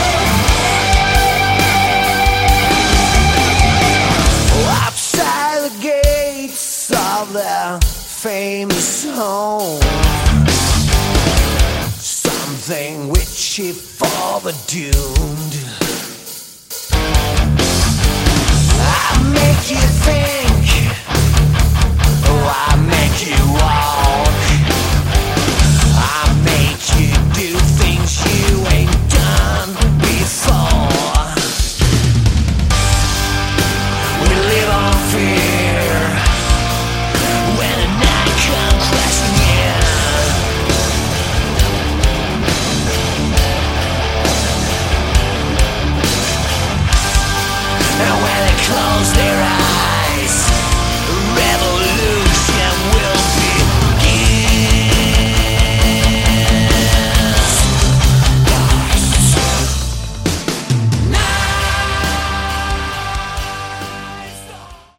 Category: Hard Rock
Lead Vocals
Bass
Guitar
Drums